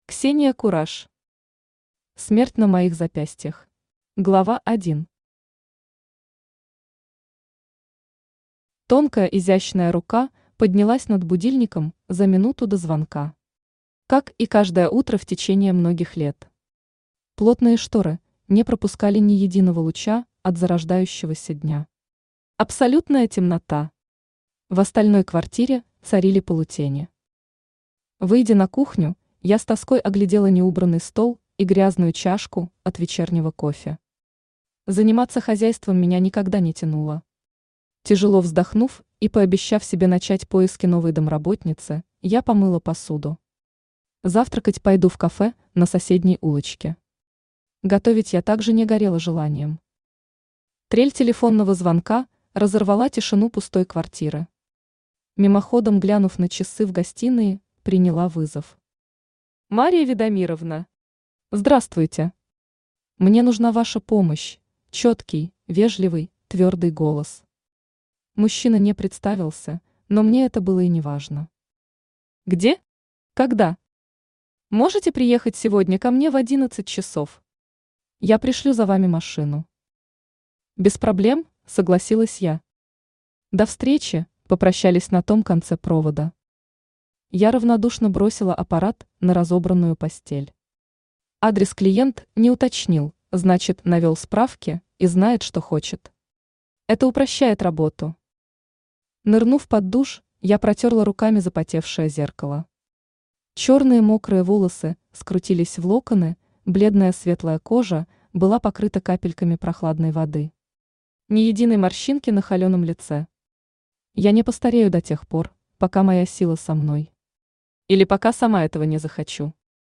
Aудиокнига Смерть на моих запястьях Автор Ксения Кураш Читает аудиокнигу Авточтец ЛитРес.